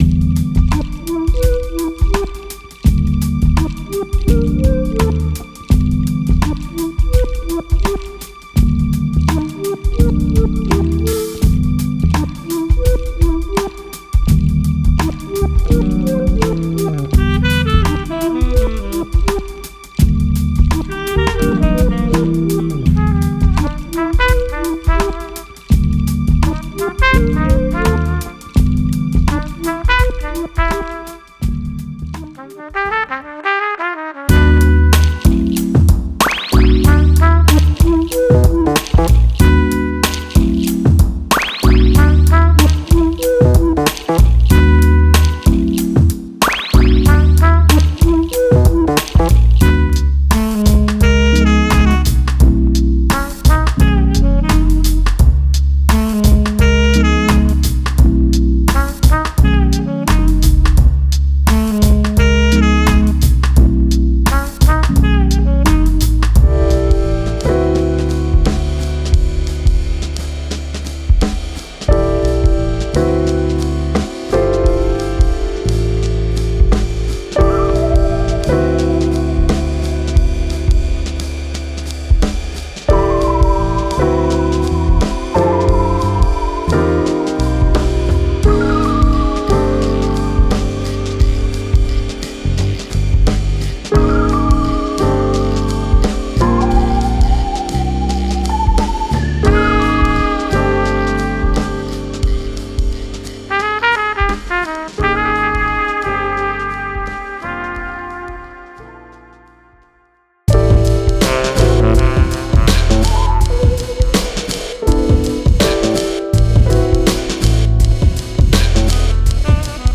Genre:Jazz
ここにあるスピリットは生きて呼吸しており、形式よりもフィーリングを重視し、メカニクスよりもムードを優先しています。
このパックは、何も無理をせず、過剰に考え込むこともない、深夜のセッションのように流れていきます。
デモサウンドはコチラ↓